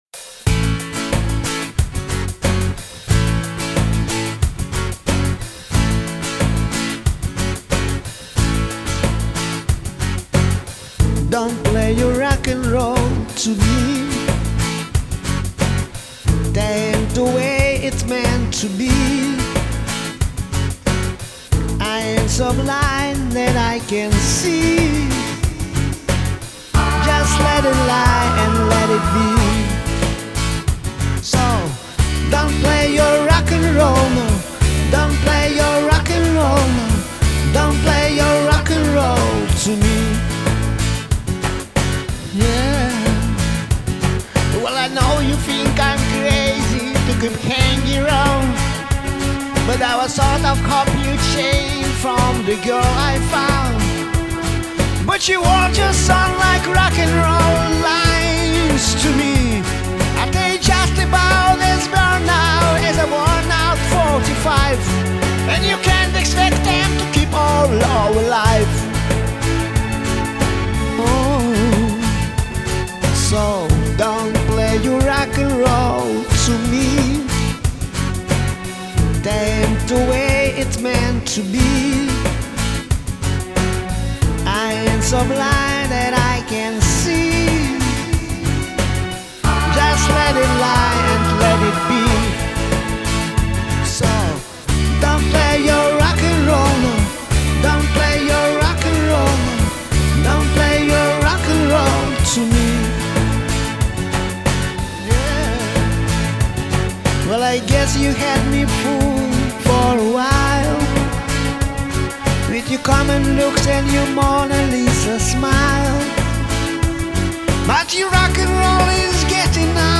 Понравились интонации в его исполнении.